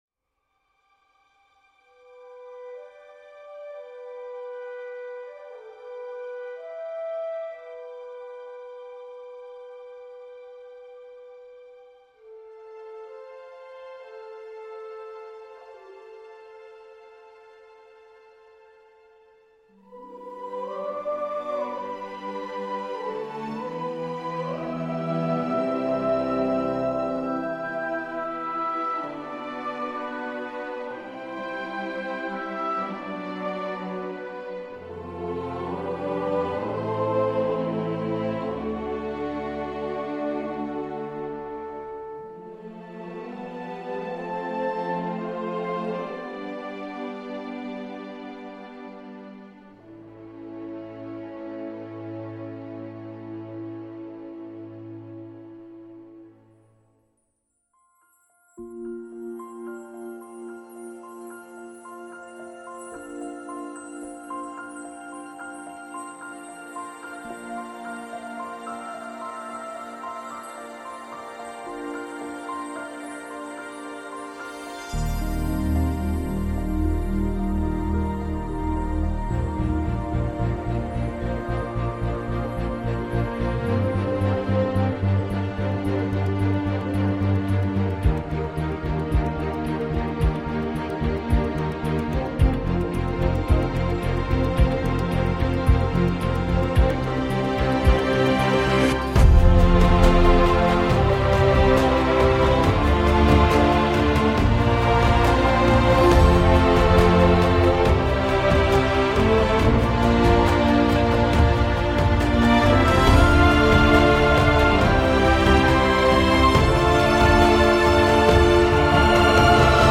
C’est riche et varié et ça prend parfois une belle ampleur.
à tendance pastorale
Une courte partition, logiquement pleine de fraîcheur.